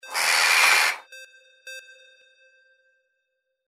Crow Jackdaw 03A
Stereo sound effect - Wav.16 bit/44.1 KHz and Mp3 128 Kbps
Tags: caw
previewANM_CROW_WBHD03A.mp3